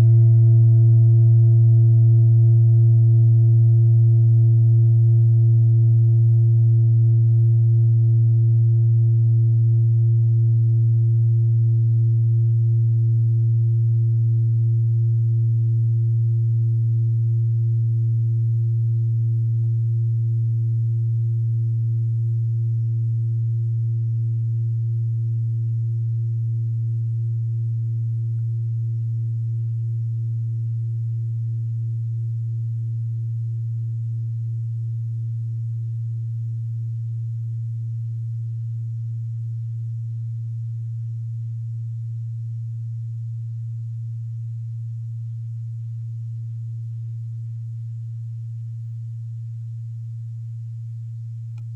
Klangschale Bengalen Nr.32
Die Klangschale kommt aus einer Schmiede in Bengalen (Ostindien). Sie ist neu und wurde gezielt nach altem 7-Metalle-Rezept in Handarbeit gezogen und gehämmert.
Der Ton des Siderischen Mondes liegt bei 227,43 Hz, das ist auf unserer Tonleiter nahe beim "B".
klangschale-ladakh-32.wav